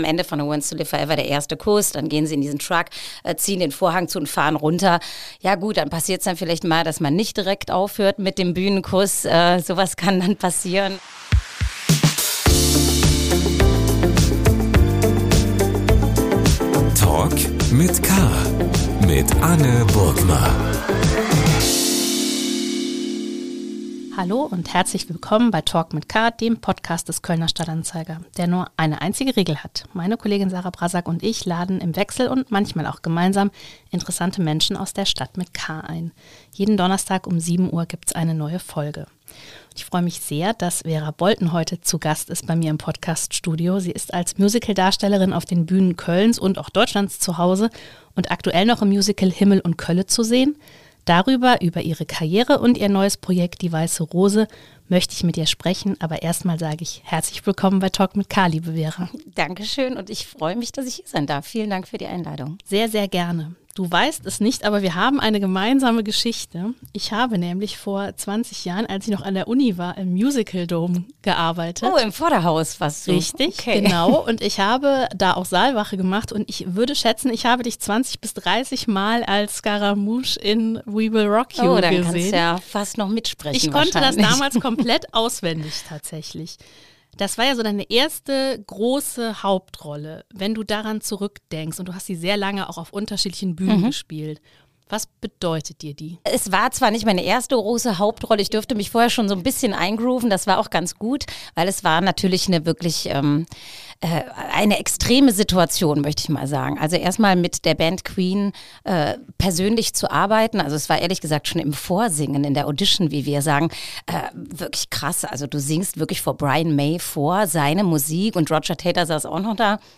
Die Sängerin im Gespräch. ~ Talk mit K - der Talk-Podcast des Kölner Stadt-Anzeiger Podcast